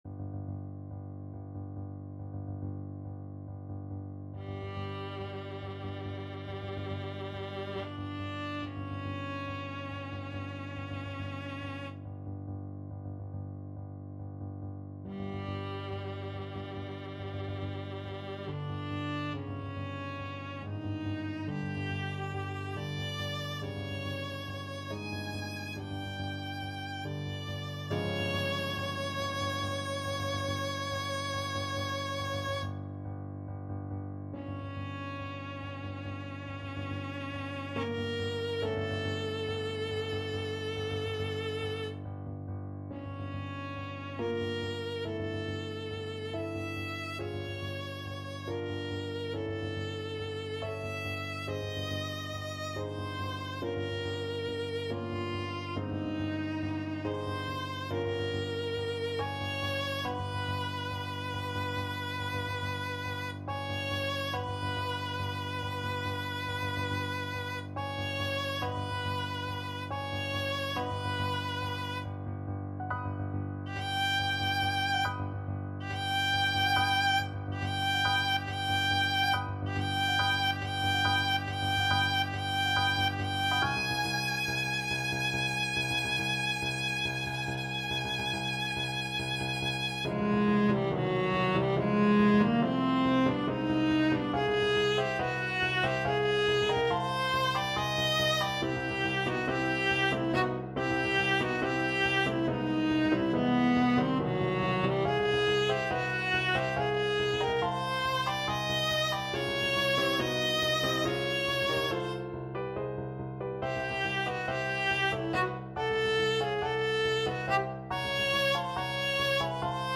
5/4 (View more 5/4 Music)
Allegro = 140 (View more music marked Allegro)
Classical (View more Classical Viola Music)